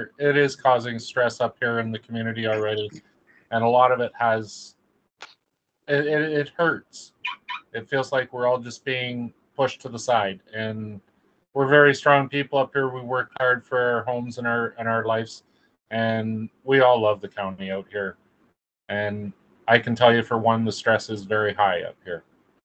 Tuesday night the county hosted a virtual public information session to detail the third iteration of the housing development near Picton.
One man, who said he is a resident of the area, gave insight to what the people in the community are feeling.